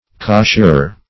Cosherer \Cosh"er*er\ (k?sh"?r-?r), n. One who coshers.